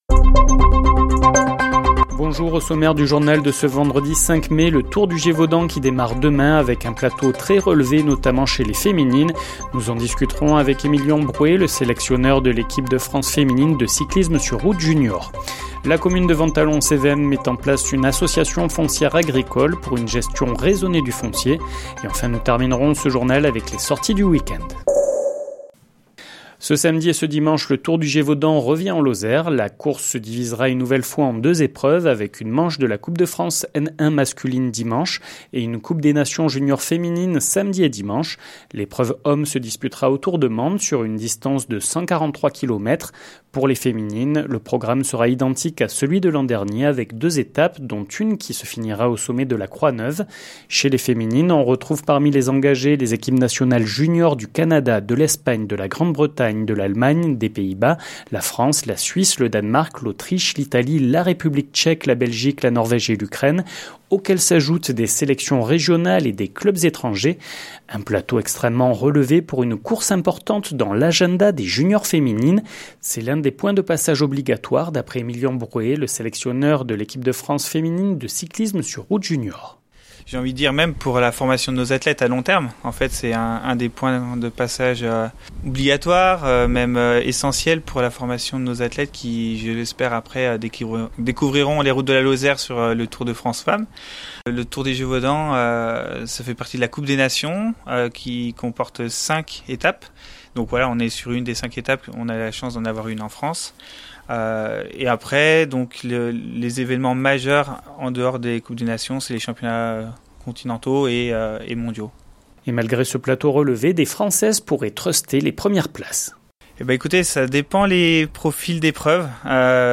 Le journal sur 48FM
Les informations locales